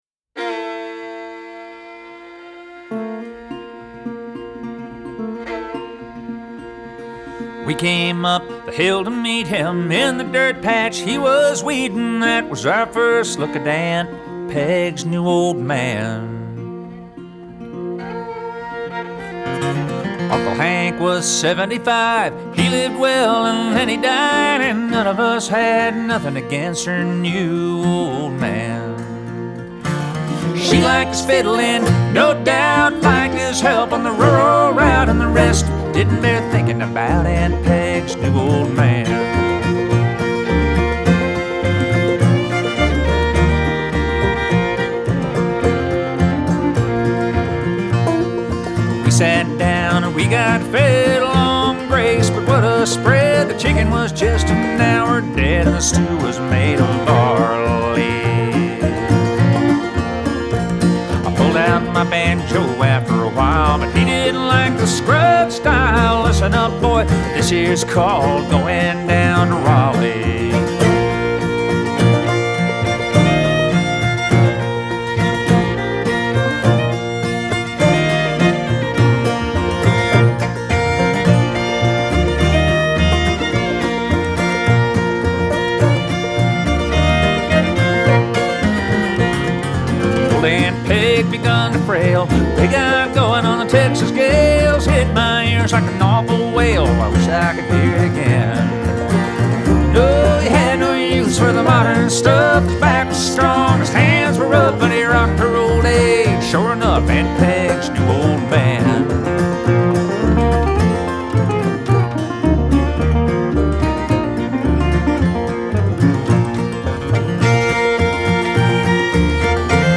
Folk and Bluegrass music